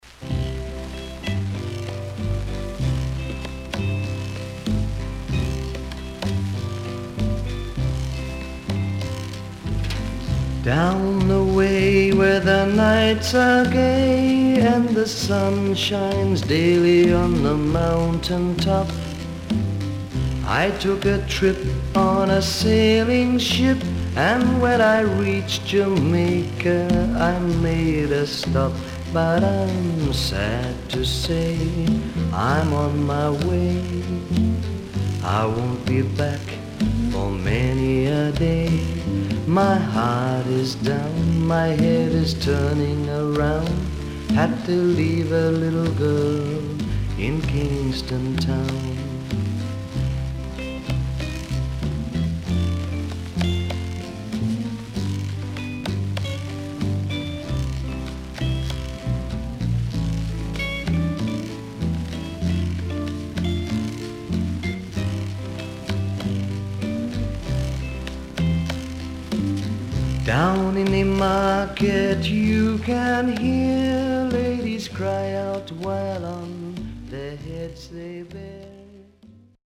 SIDE B:少しノイズあり、曲によってヒスが入りますが良好です。